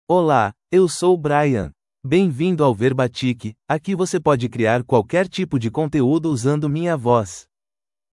Brian — Male Portuguese (Brazil) AI Voice | TTS, Voice Cloning & Video | Verbatik AI
Brian is a male AI voice for Portuguese (Brazil).
Voice sample
Listen to Brian's male Portuguese voice.
Brian delivers clear pronunciation with authentic Brazil Portuguese intonation, making your content sound professionally produced.